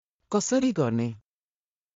当記事で使用された音声（ネパール語および日本語）は全てGoogle翻訳　および　Microsoft TranslatorNative Speech Generation、©音読さんから引用しております。